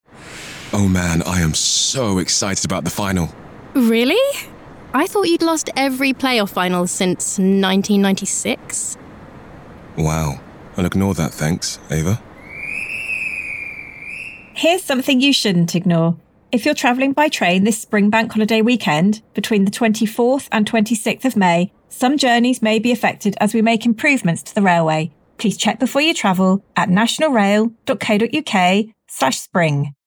Radio advert
Network-Rail-CBYT-Late-May-National-Radio-30sec.mp3